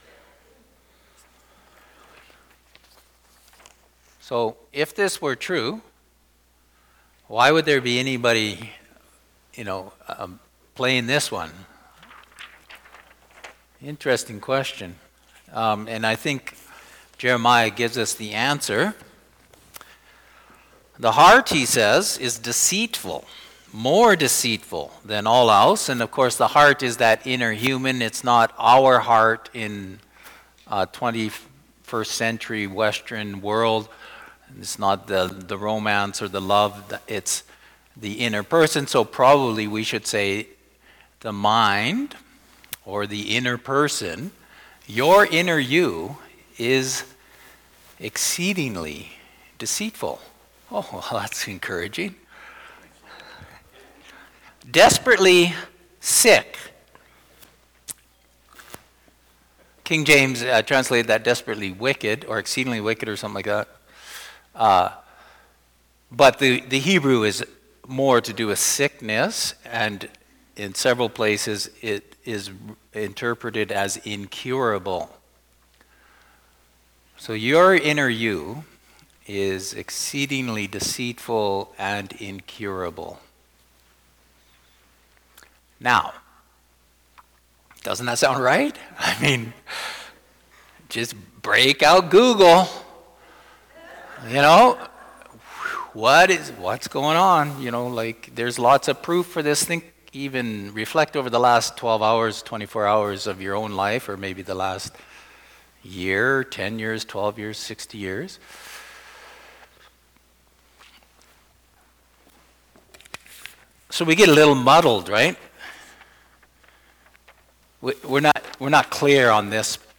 FEBRUARY_16TH_SERMON.mp3